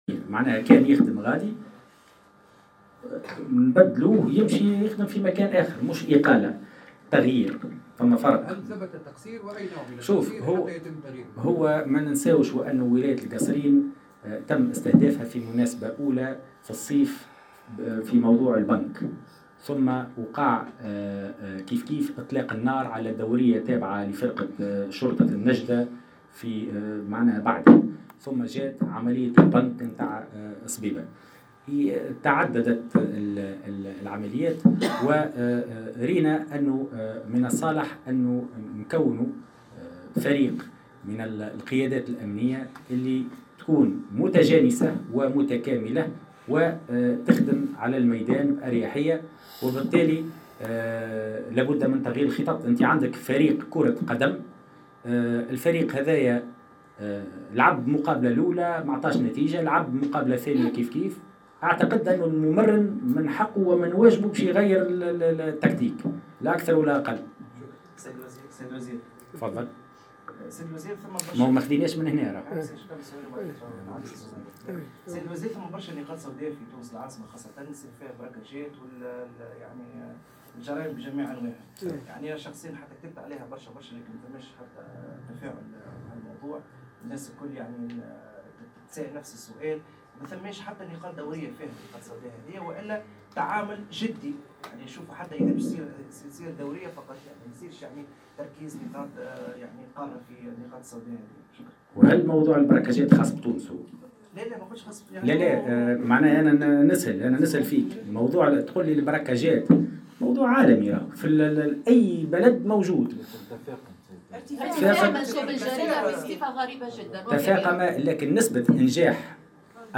وقال الوزير في ندوة صحفية عقب جلسة استماع مغلقة له بلجنة الأمن والدفاع اليوم بمجلس نواب الشعب، إن هناك محاولات لتأمين المدارس ضد هذه الظاهرة الخطيرة مقرا في ذات الوقت بأن تأمين كل المدارس عملية شبه مستحيلة.